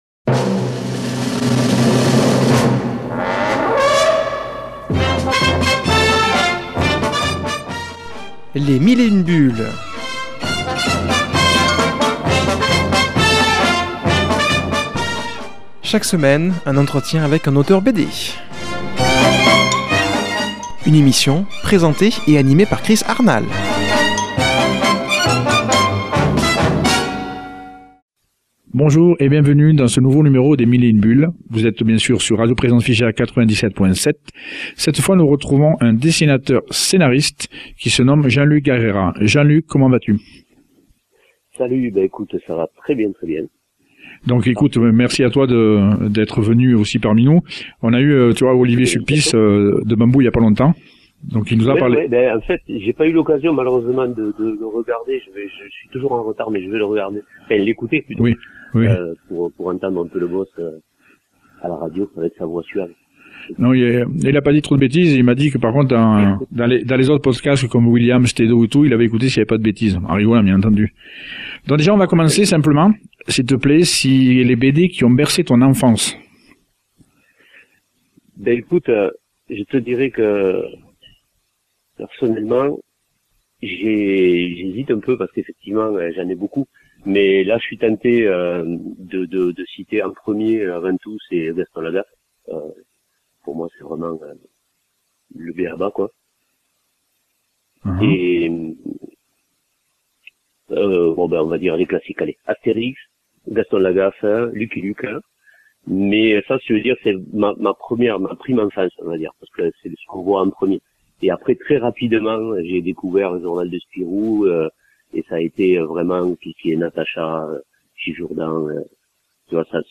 qui a comme invitée au téléphone